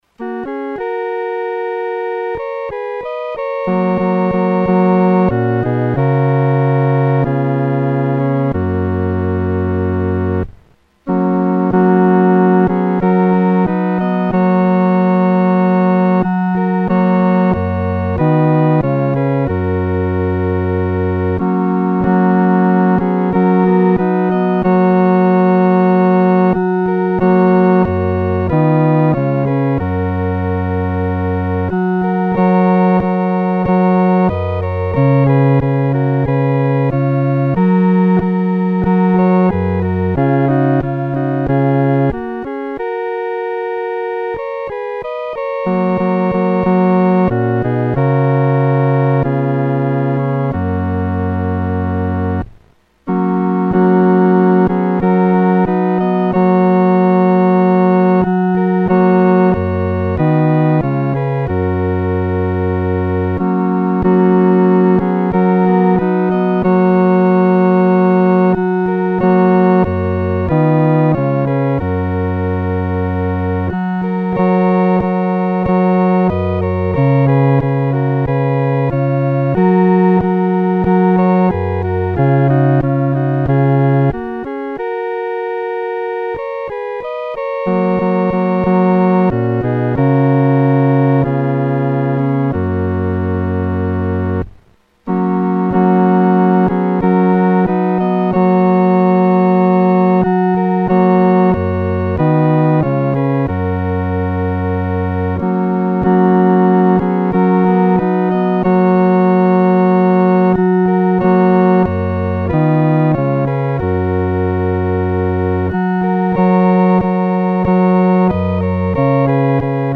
独奏（第四声）
曲调欢快、明朗